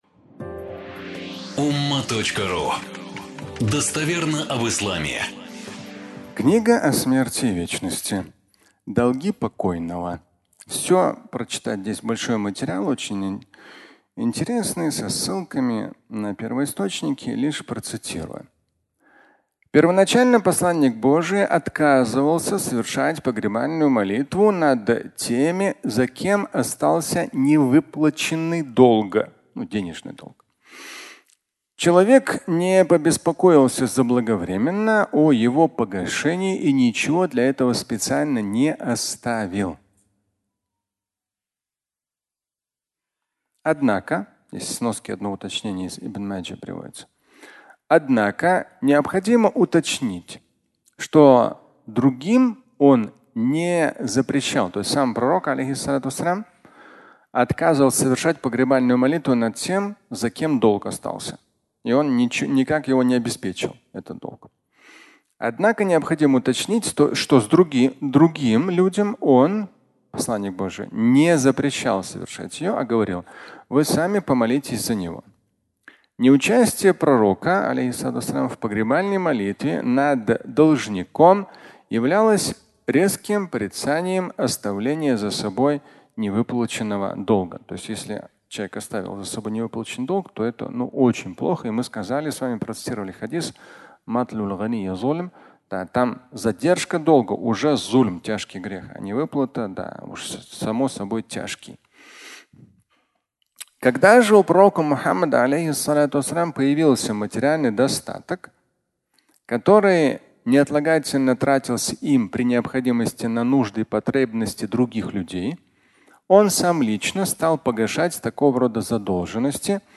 Погребальная молитва (аудиолекция)